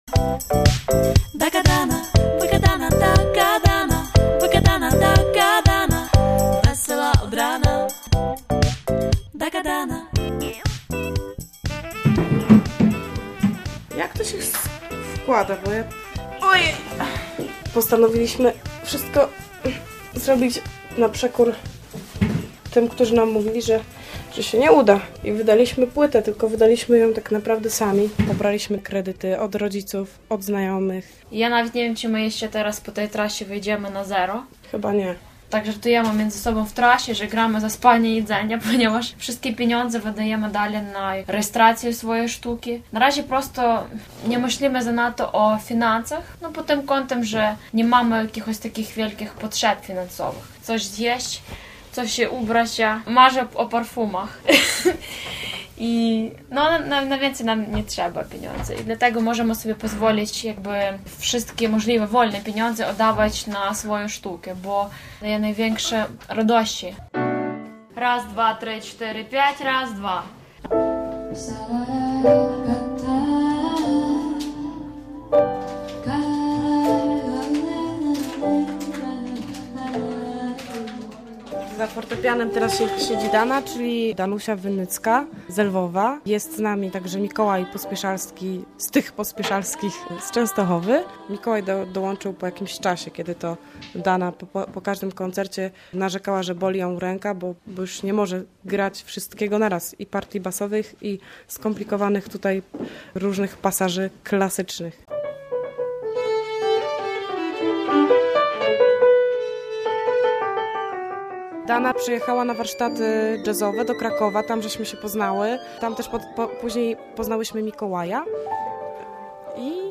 DAGADANA - reportaż